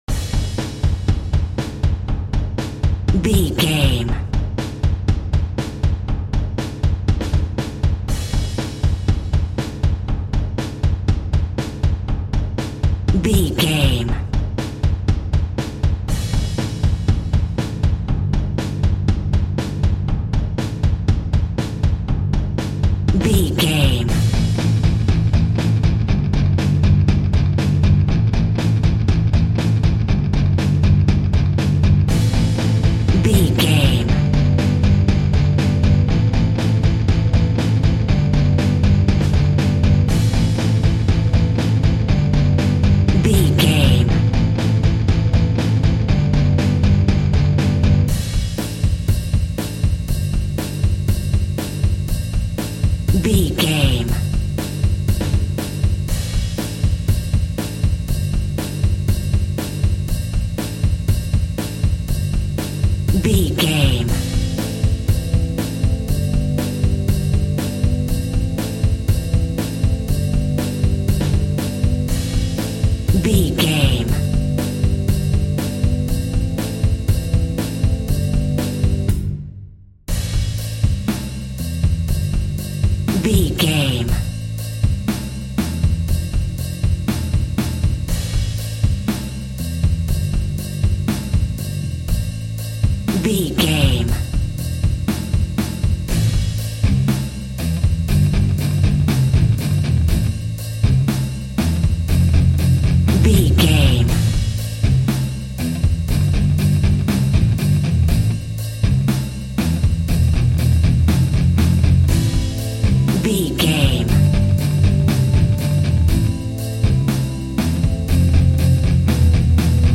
Aeolian/Minor
heavy metal
heavy rock
distortion
Instrumental rock
drums
bass guitar
electric guitar
piano
hammond organ